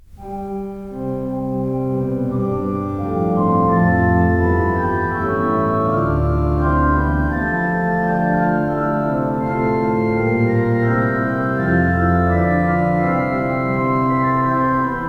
Stereo recording made 10-11 March 1960
in St John’s College Chapel, Cambridge